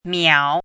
chinese-voice - 汉字语音库
miao3.mp3